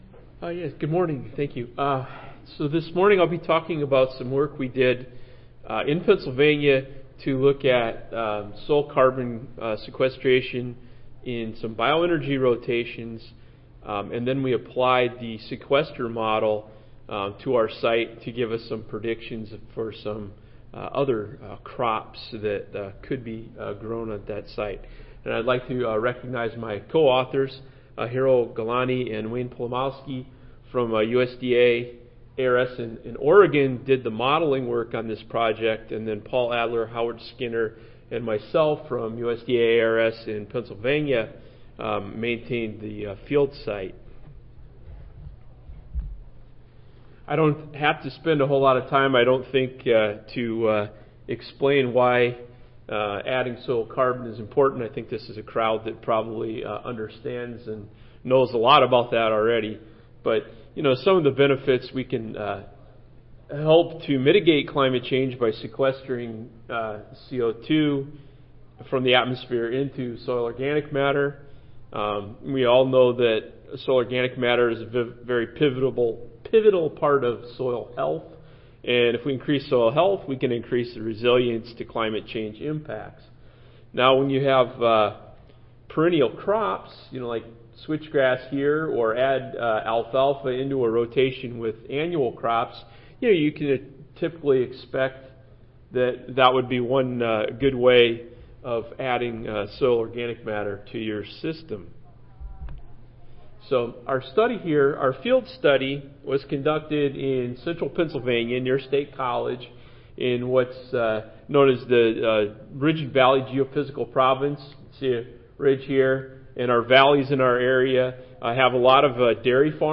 Session: Soil Carbon and Greenhouse Gas Emissions General Oral II (ASA, CSSA and SSSA International Annual Meetings)
Recorded Presentation